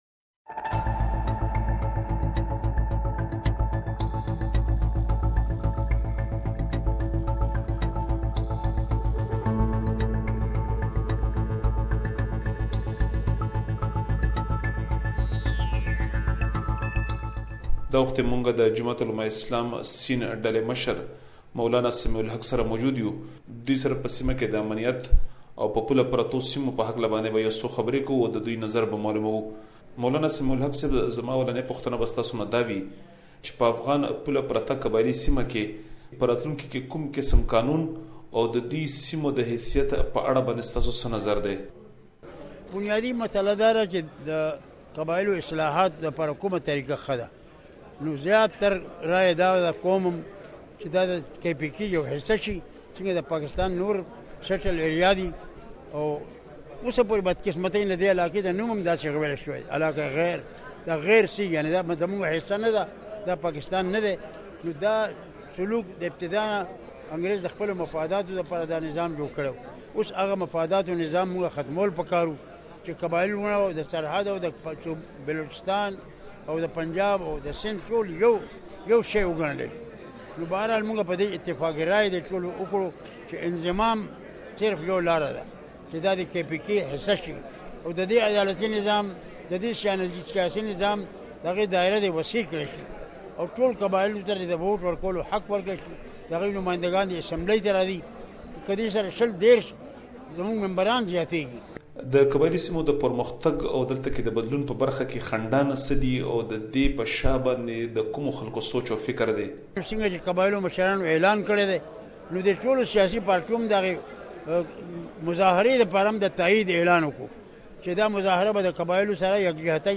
Exclusive interview with Mawlana Sami-ul-Haq